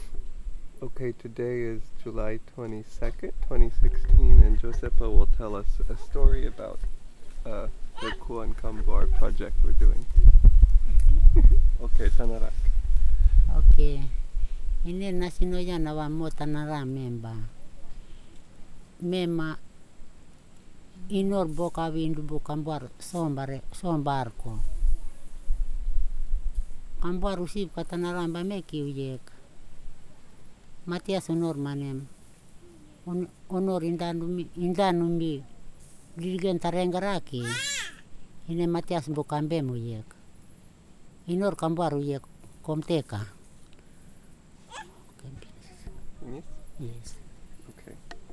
Brem, Qkuan Kambuar dialect
digital wav file recorded on Zoom H2n digital recorder
Sabente, Madang Province, Papua New Guinea